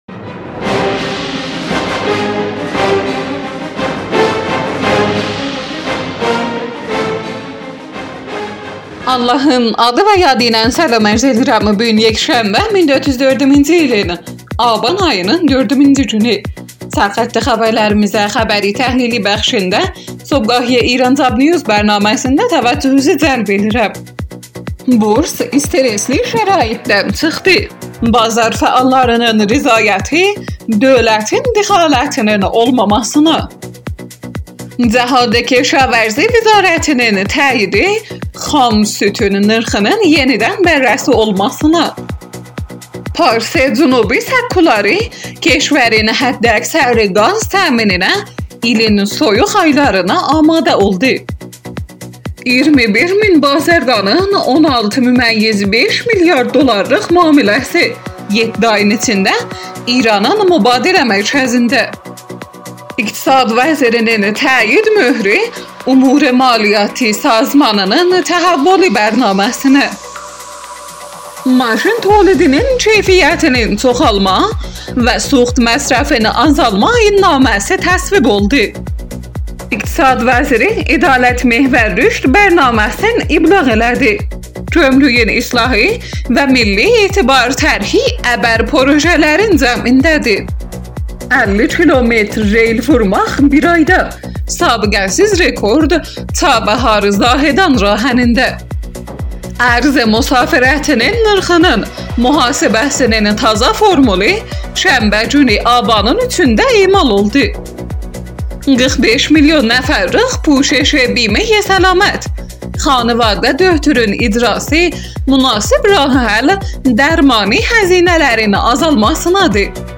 Iranjobnews Səhər xəbərləri. یکشنبه ۴ آبان ۱۴۰۴ – ترکی آذربایجانی